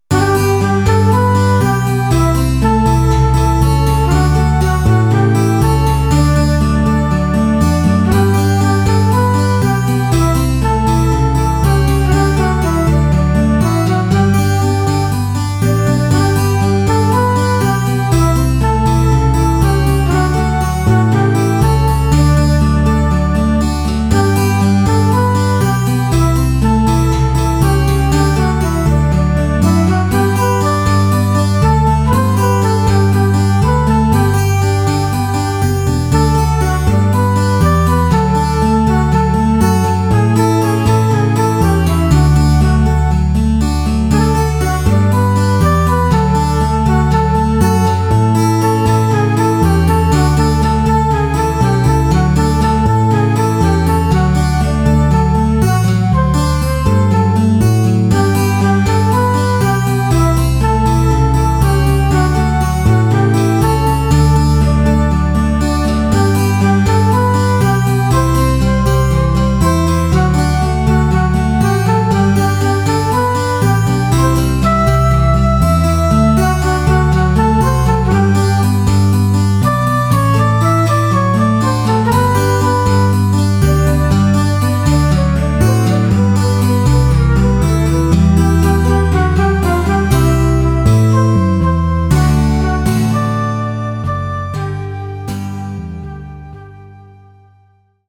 ein berühmtes Duett